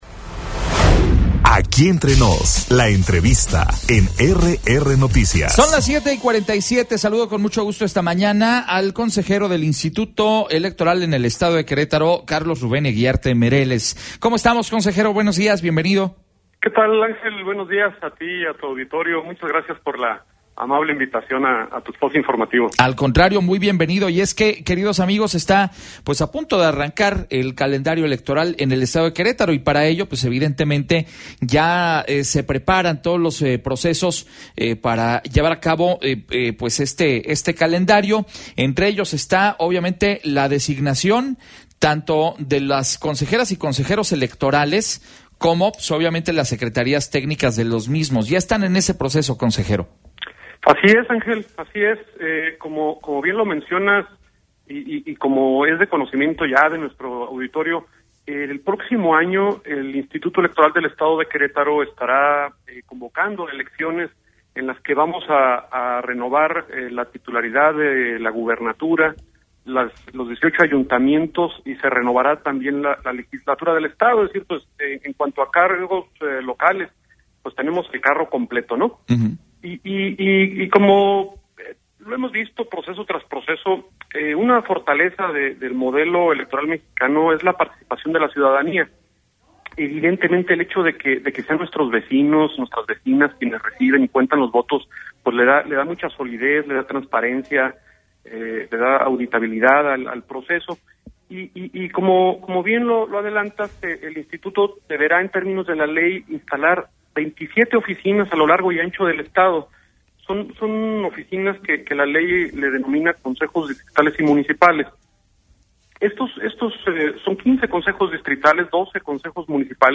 EntrevistasMultimediaPodcast